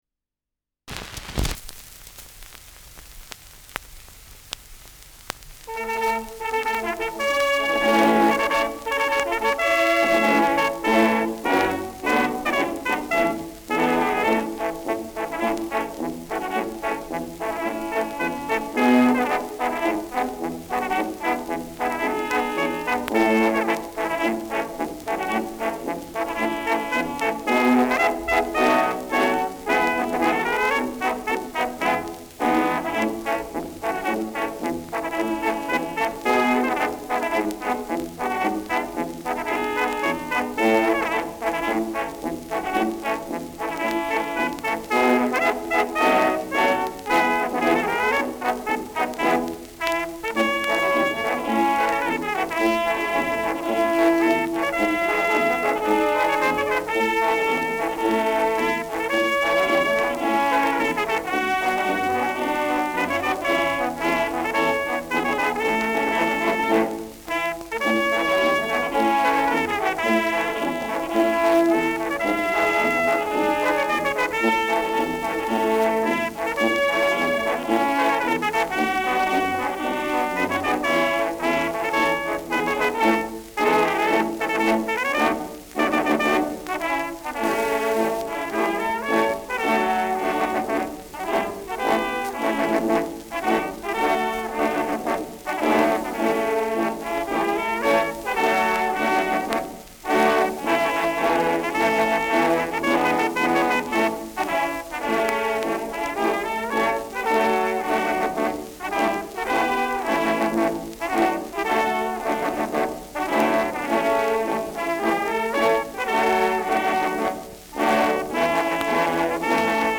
Schellackplatte
Zu Beginn stärkeres Grundrauschen : Gelegentlich leichtes bis stärkeres Knacken
Kapelle Greiß, Nürtingen (Interpretation)
[Stuttgart] (Aufnahmeort)